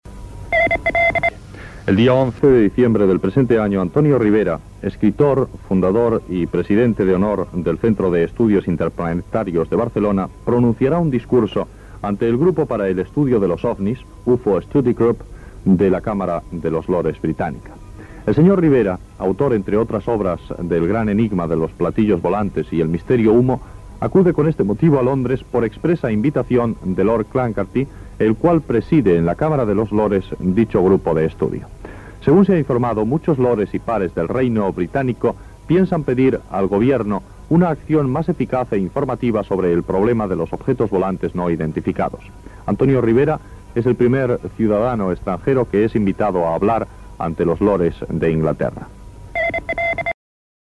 Breve comunicado de radio donde se anuncia la próxima intervención de Ribera en la cámara de los lores británica.